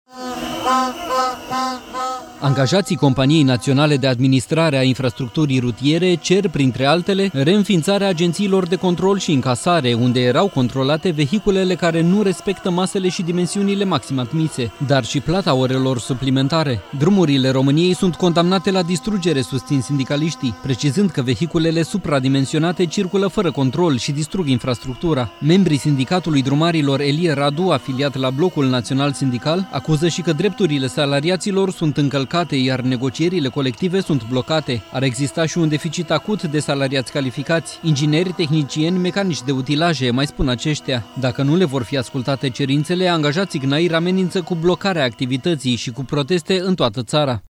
Protest al angajaților CNAIR în fața Ministerului de Finanțe. Sindicaliștii acuză un „haos legislativ”, dar și un dezastru rutier și social.